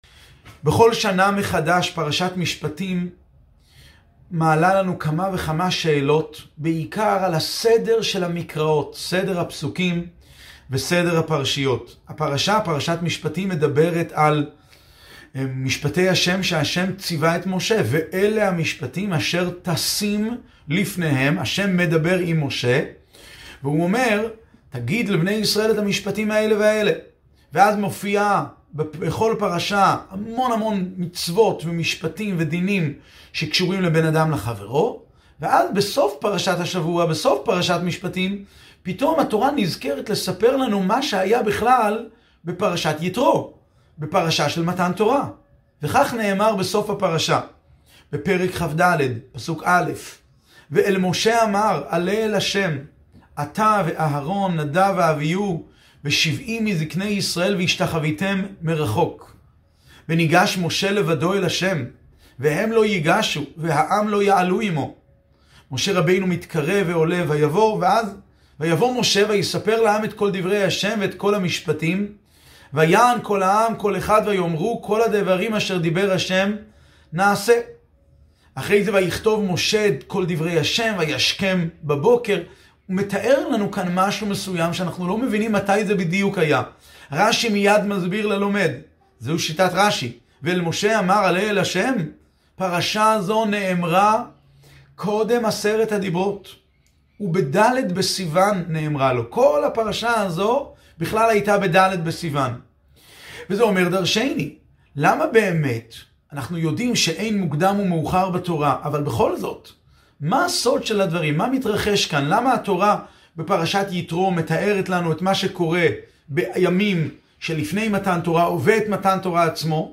שיעור בעיון עברית